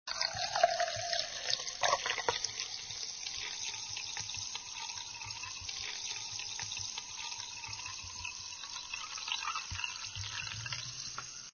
pourdrink.mp3